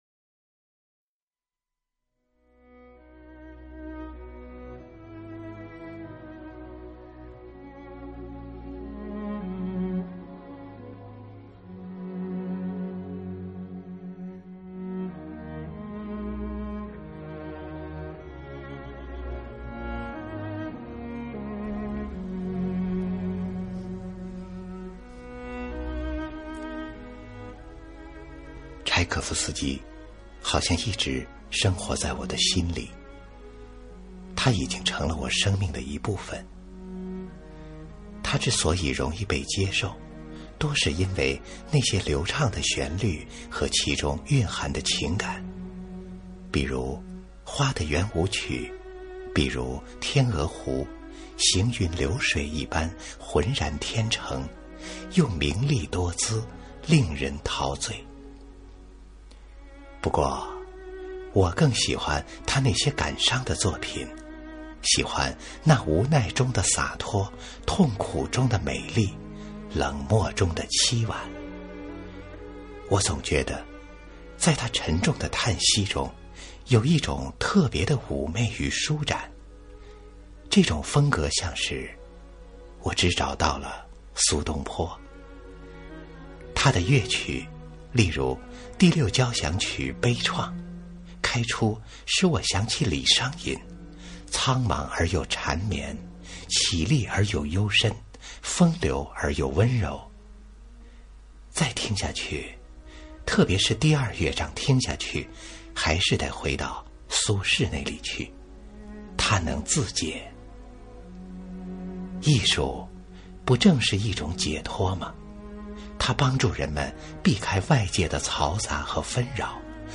经典朗诵欣赏
新派朗诵——用声音阐释情感